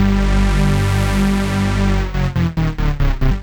FR_Wavee_140-G.wav